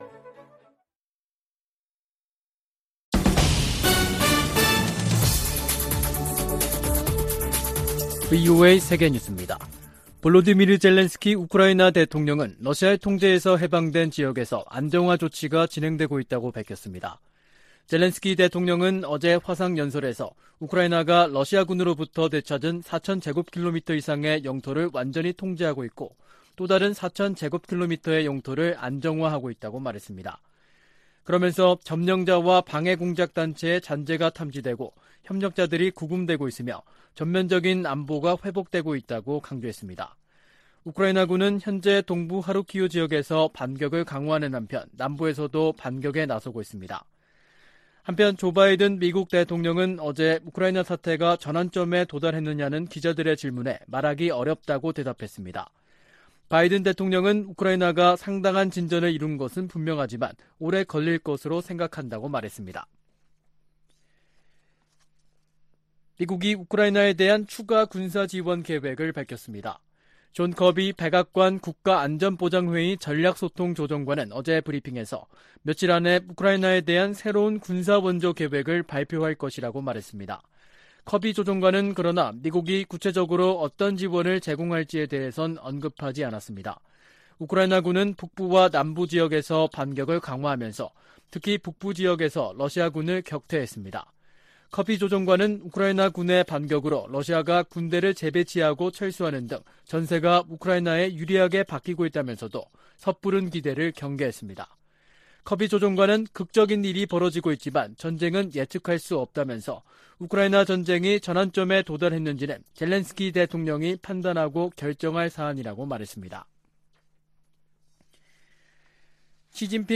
VOA 한국어 간판 뉴스 프로그램 '뉴스 투데이', 2022년 9월 14일 3부 방송입니다. 북한의 핵 무력정책 법제화가 유일지배체제의 근본적 모순을 드러내고 있다고 전문가들이 분석하고 있습니다. 미 상원의원들이 북한의 핵 무력정책 법제화에 우려와 비판의 목소리를 내고 있습니다. 유엔 인권기구가 북한 지도부의 코로나 규제 조치로 강제노동 상황이 더 악화했을 수 있다고 경고했습니다.